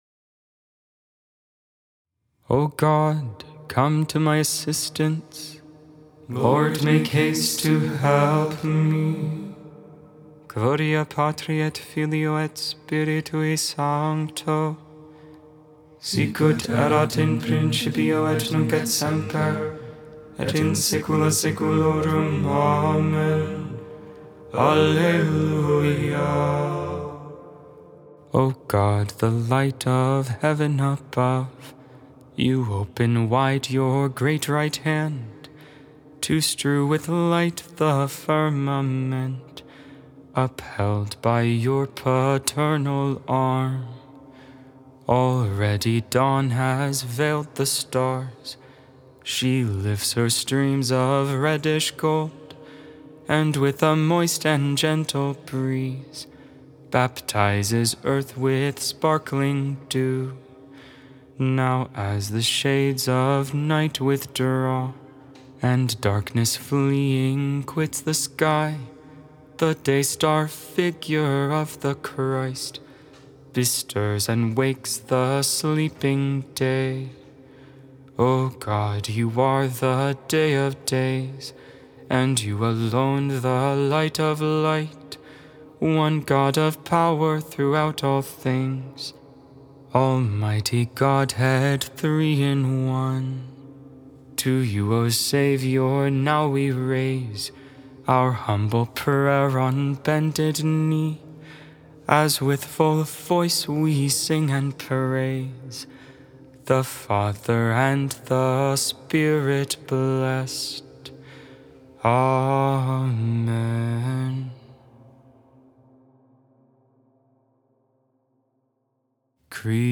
Ambrosian hymn ca. 5th century.